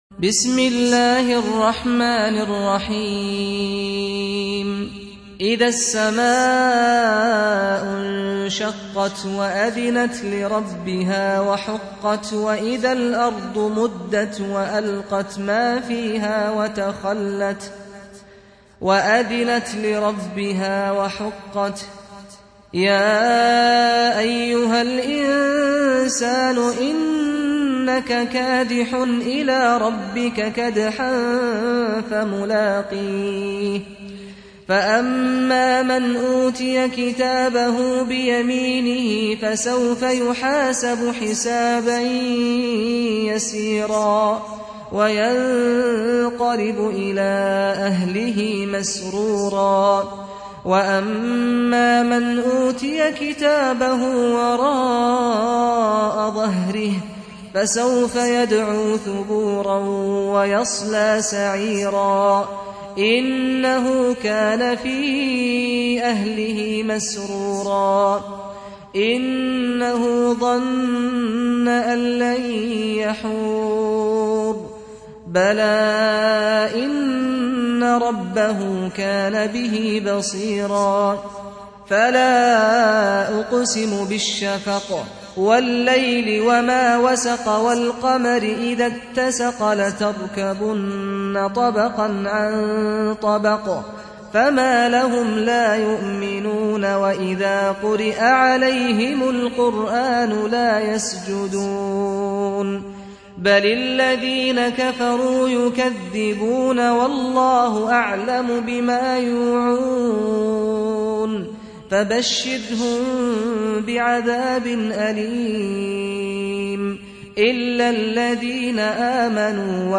84. Surah Al-Inshiq�q سورة الإنشقاق Audio Quran Tarteel Recitation
Surah Repeating تكرار السورة Download Surah حمّل السورة Reciting Murattalah Audio for 84.